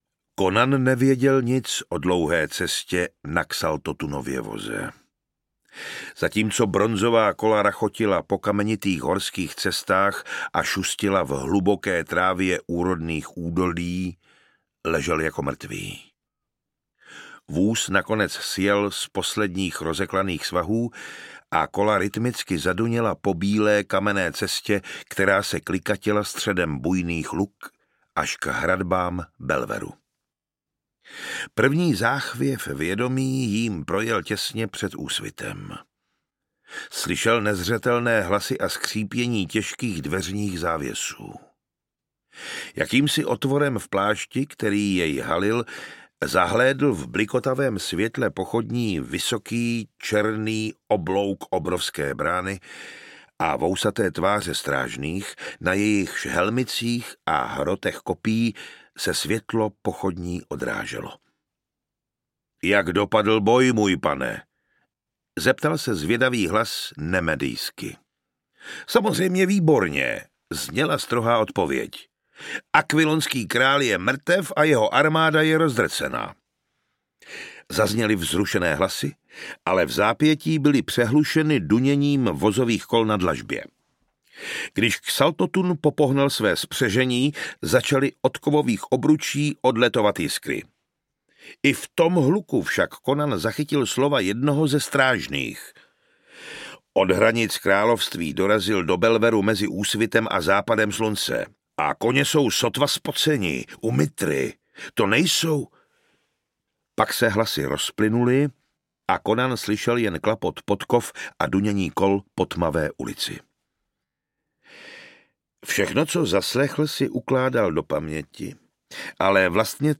Conan - Hodina draka audiokniha
Ukázka z knihy